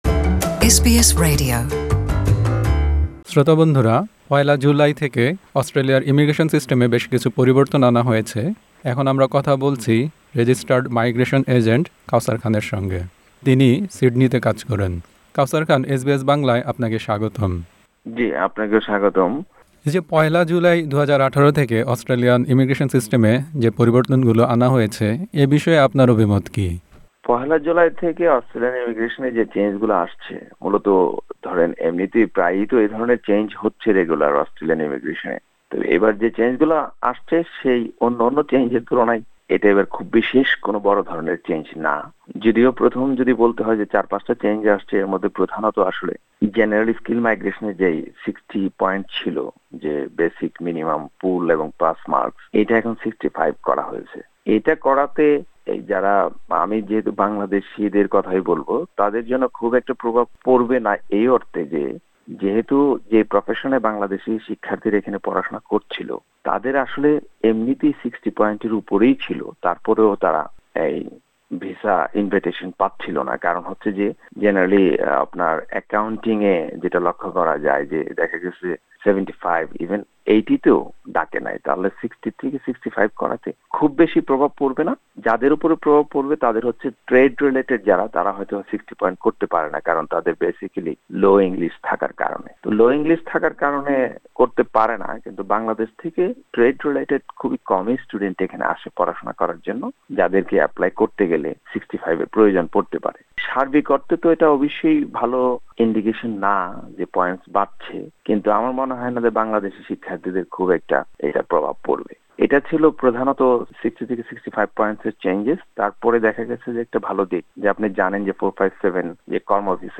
সাক্ষাৎকারটি